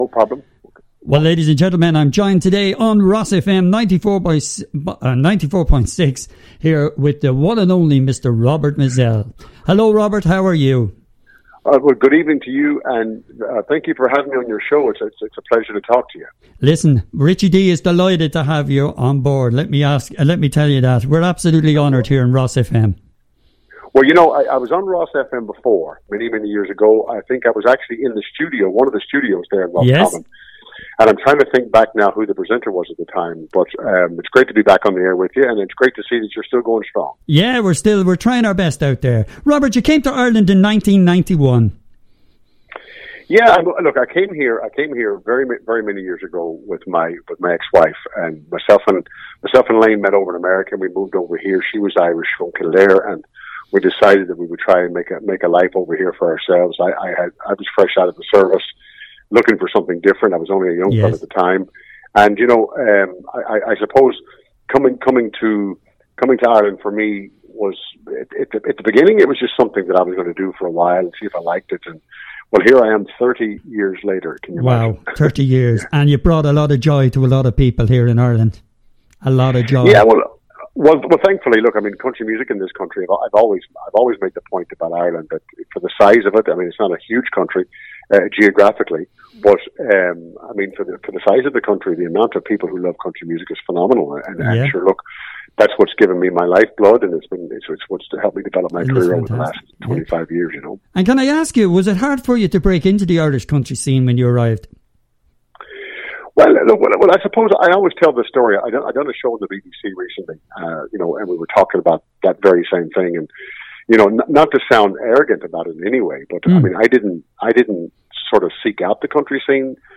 Country Superstar Robert Mizzell Interview - RosFM 94.6